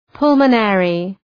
Προφορά
{‘pʌlmə,nerı}
pulmonary.mp3